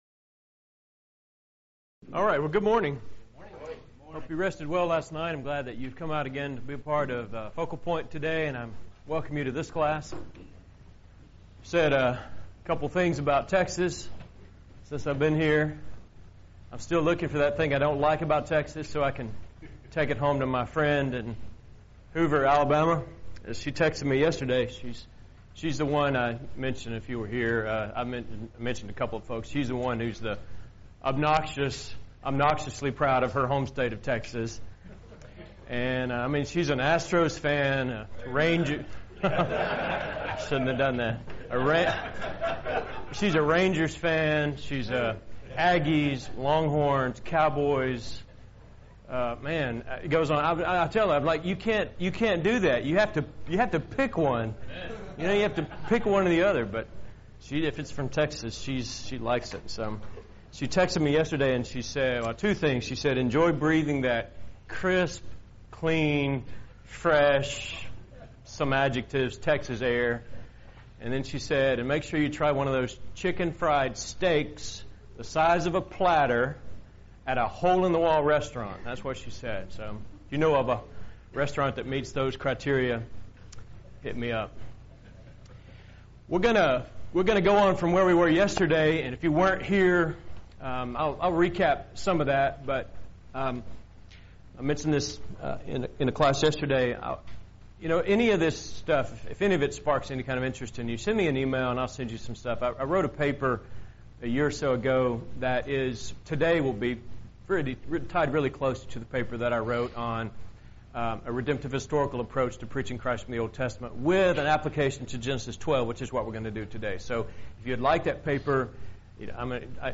Event: 2018 Focal Point Theme/Title: Preacher's Workshop
lecture